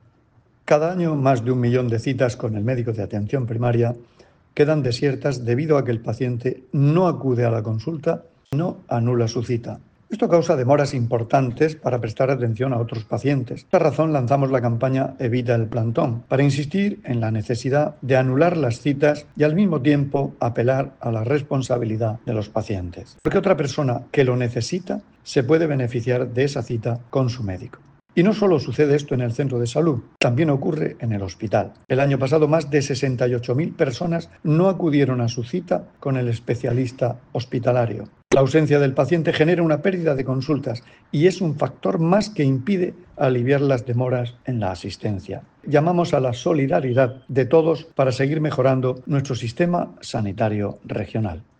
Declaraciones del consejero de Salud, Juan José Pedreño, sobre la campaña 'Evita el plantón'.